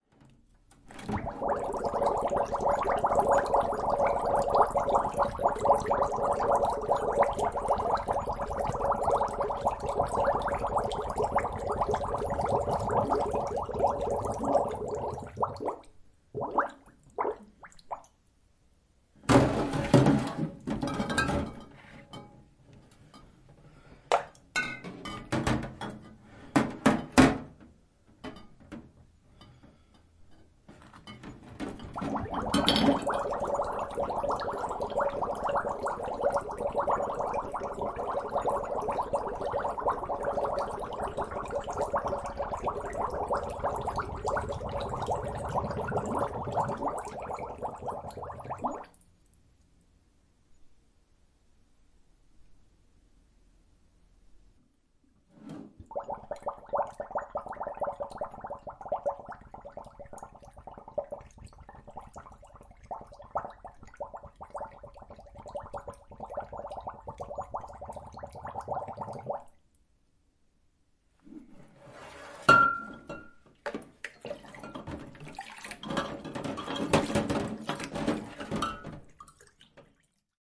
金属水瓶运动充填和排空
描述：处理金属运动水壶，用水填充，然后在水槽中再次清空。
标签： 填充 填充 金属瓶 倾倒
声道立体声